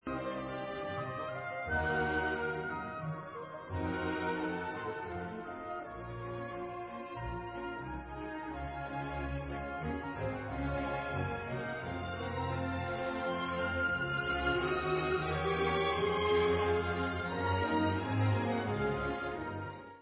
B dur (Moderato, quasi minuetto) /Polonéza